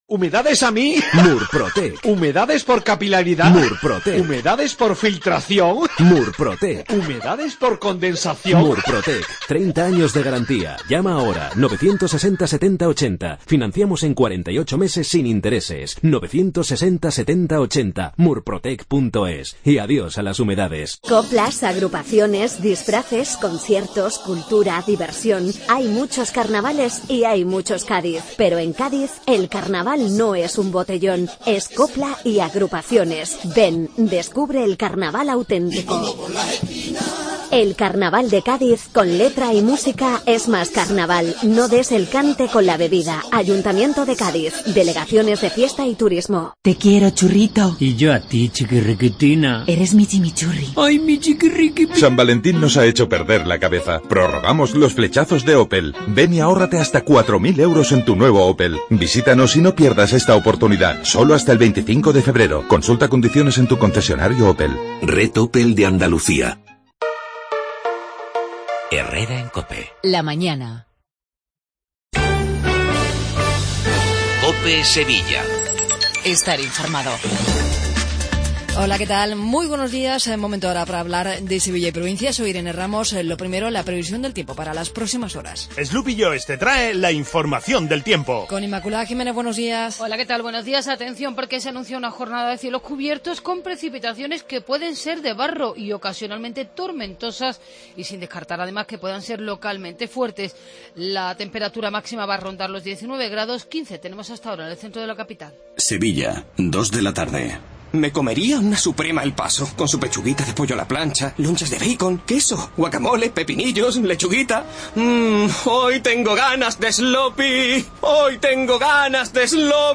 INFORMATIVO LOCAL MATINAL 8:20